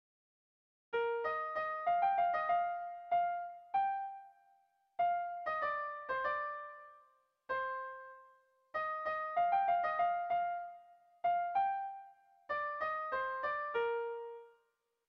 Dantzakoa
A1A2